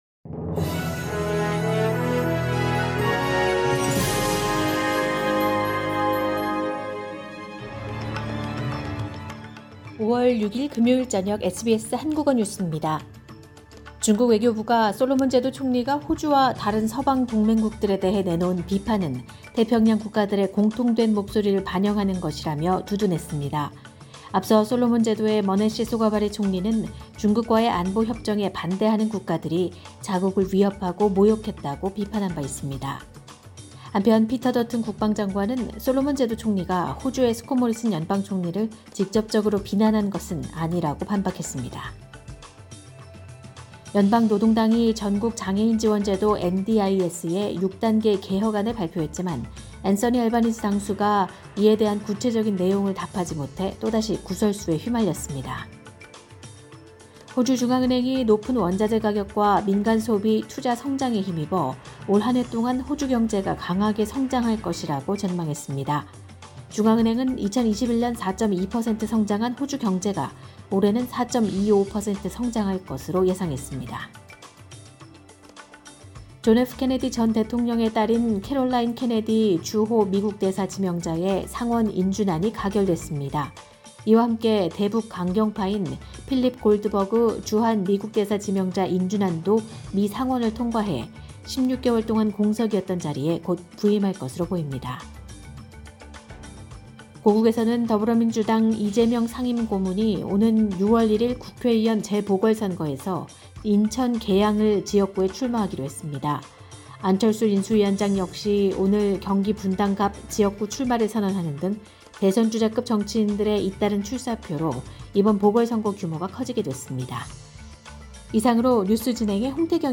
SBS 한국어 저녁 뉴스: 2022년 5월 6일 금요일